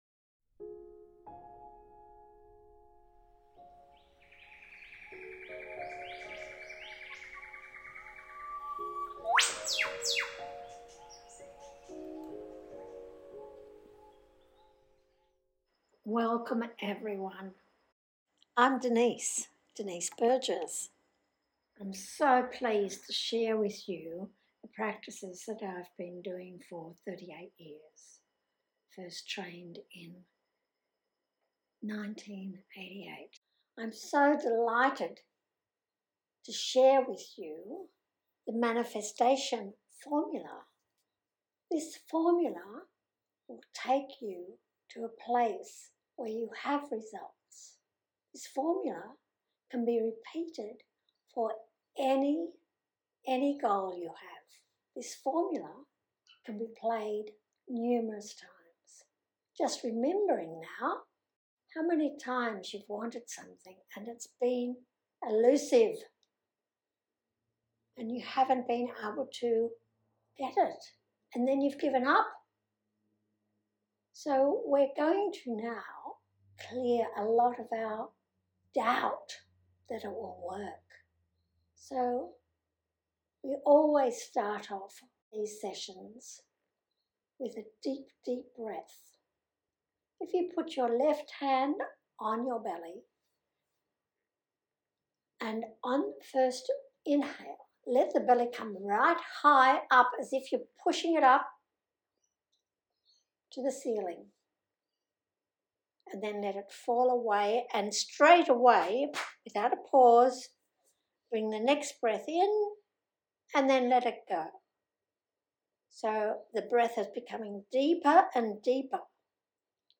Manifestation Meditation